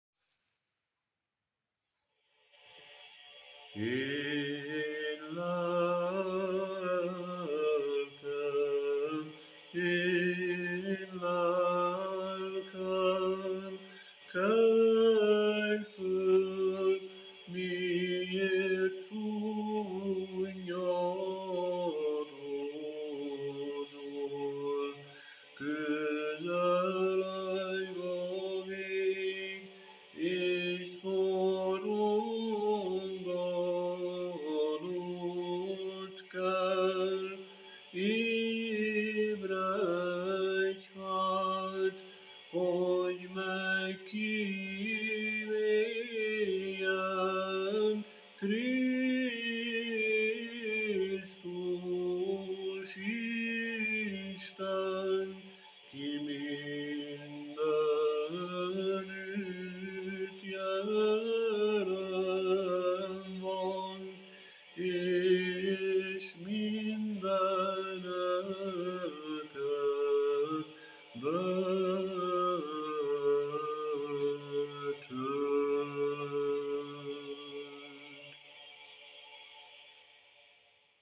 Böjti kondákion 6. hang: